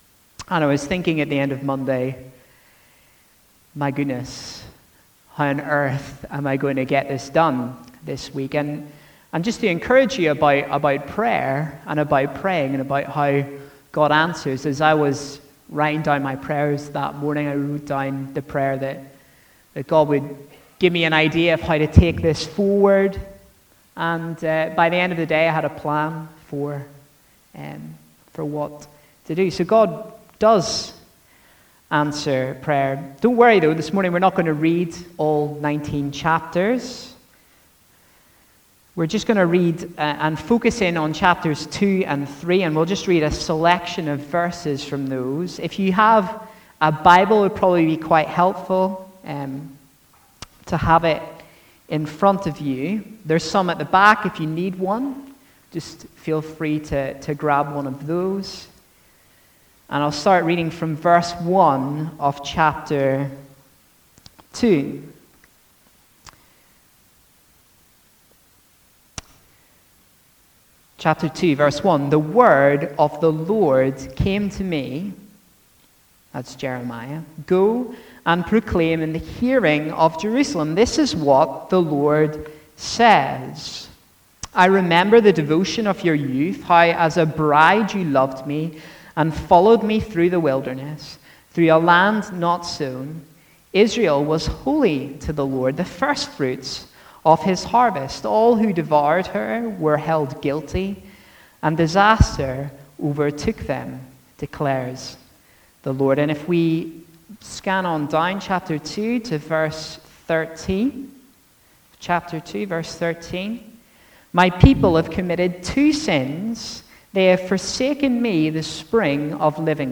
A message from the series "Jeremiah."